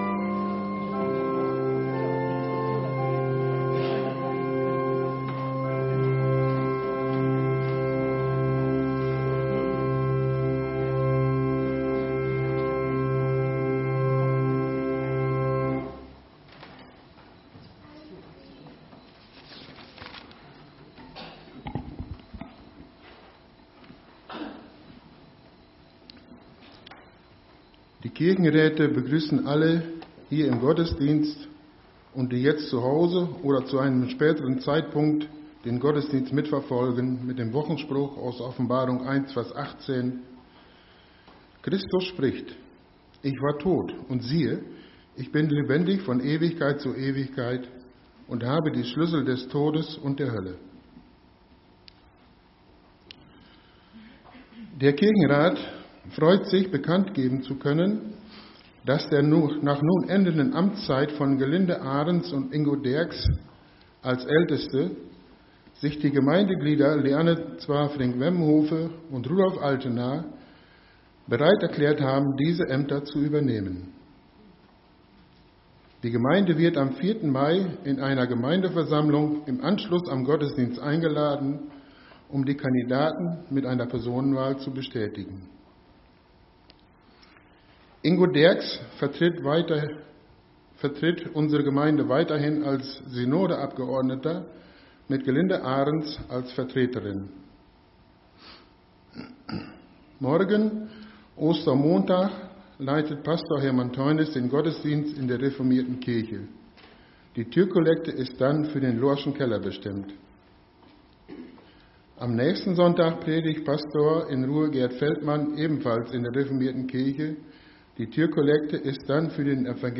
Wir laden ein, folgende Lieder aus dem Evangelischen Gesangbuch mitzusingen: Lied 177, 2, Lied 100, 1 – 5, Psalm 118, 1 + 5 + 10, Lied 115, 1 – 3 + 5, Lied 225, 1 – 3, Lied 560, 1 – 4, Lied 116, 1 + 2 + 5